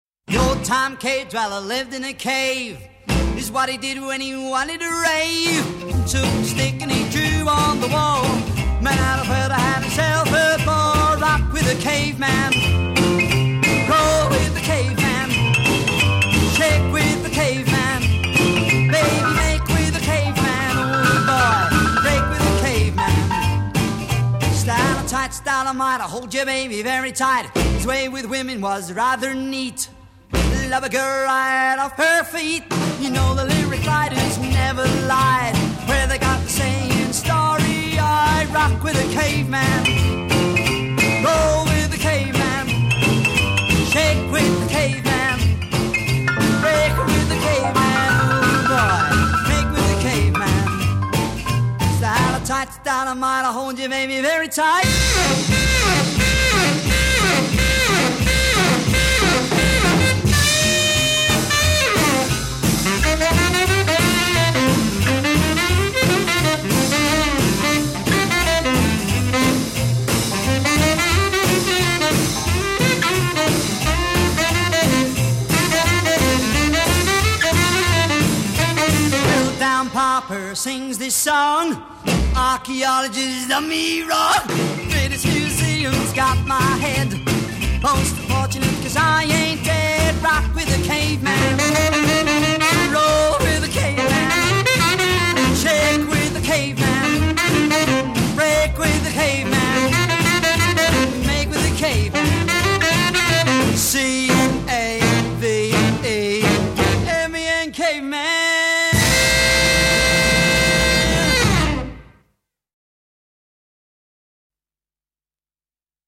tenor sax
stand-up slap bass
piano
drums), and an unidentified electric guitarist.
B verse : 18 saxophone solo *